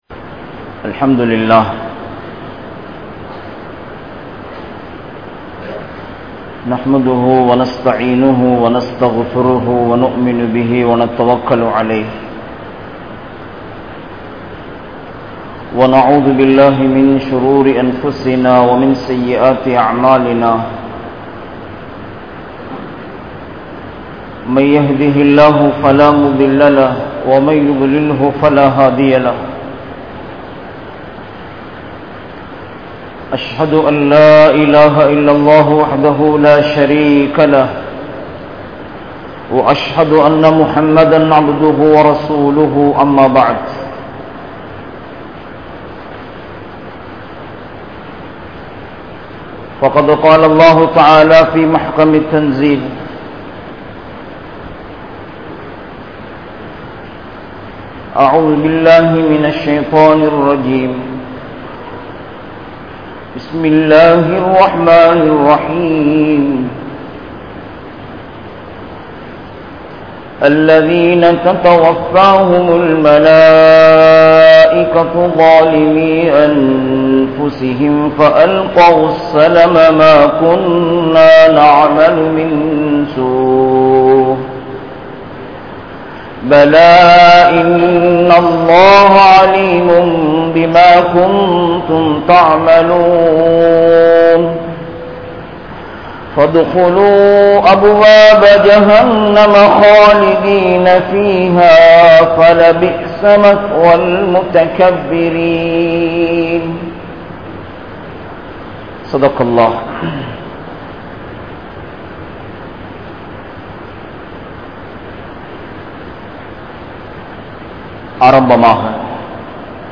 Are you Ready For Death? | Audio Bayans | All Ceylon Muslim Youth Community | Addalaichenai